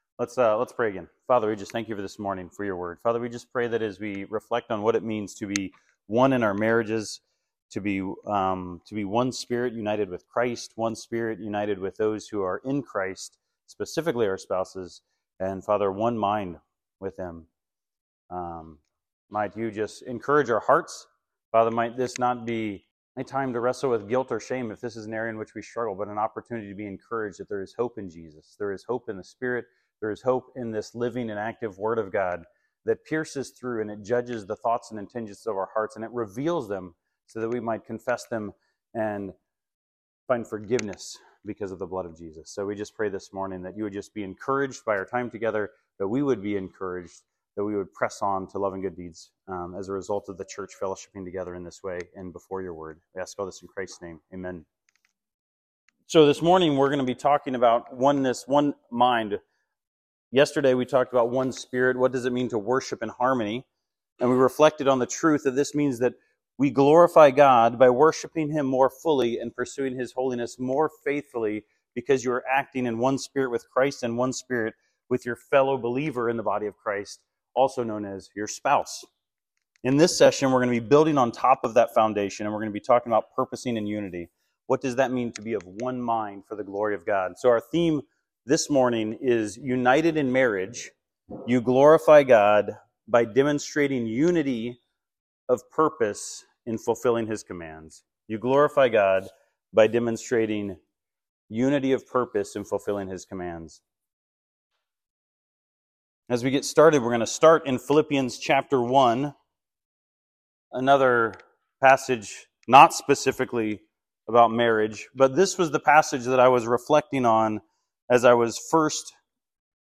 Beginnings Beginnings - Spring Retreat 2025 - Oneness in Marriage Audio Outline ◀ Prev Series List Next ▶